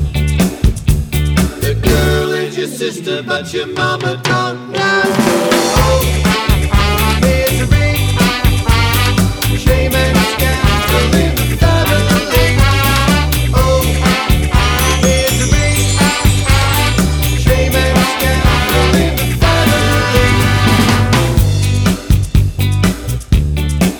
With Extra Snare Ska 2:55 Buy £1.50